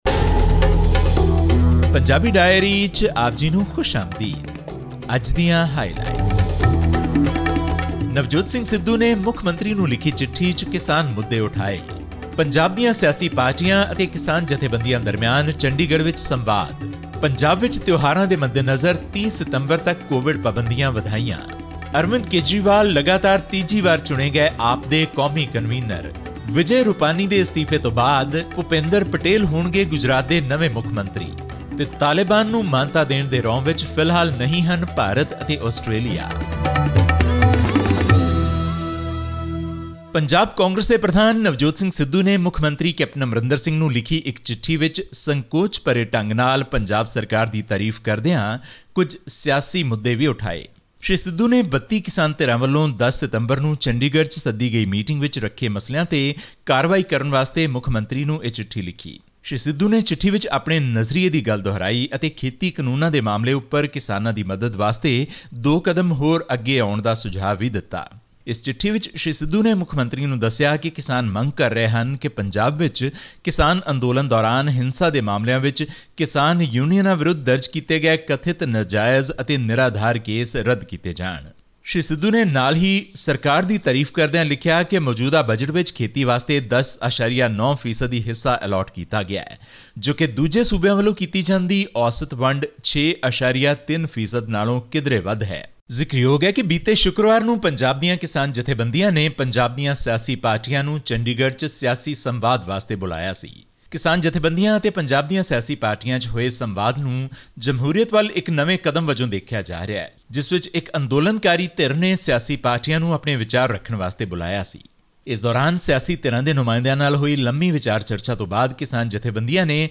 Representatives of over two dozen farmer unions held a meeting with all political parties, barring the Bharatiya Janata Party (BJP) in Chandigarh on 10 September, making it clear that farm laws will form the core agenda for the forthcoming state elections. All this and more in our weekly news bulletin from Punjab.